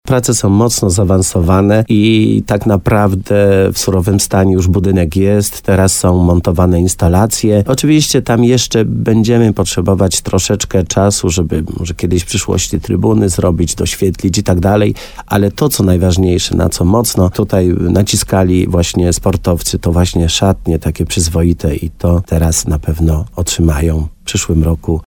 Jednak to, co najważniejsze, na co mocno naciskali sportowcy, to przyzwoite szatnie, które teraz na pewno otrzymają w przyszłym roku – mówi wójt gminy Korzenna, Leszek Skowron.